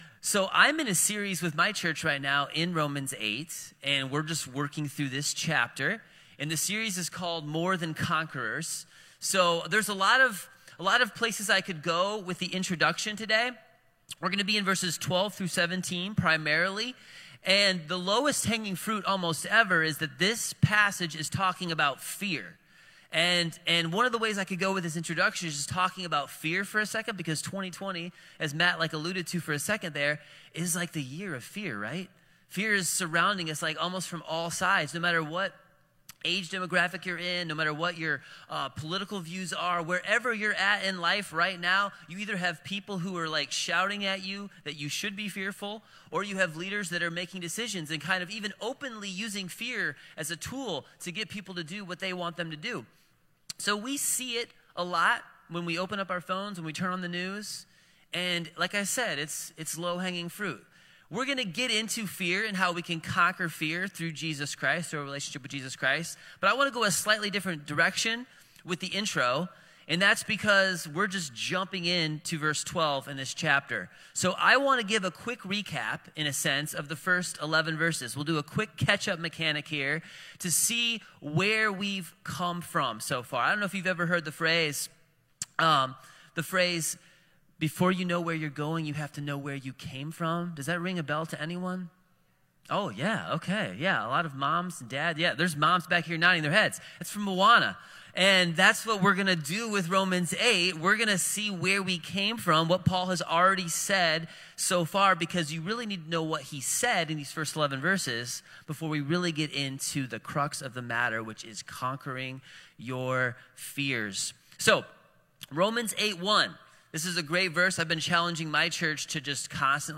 Sermon1129_Fearless-Adopted-Heirs.m4a